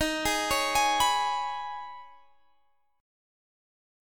Eb7sus4#5 chord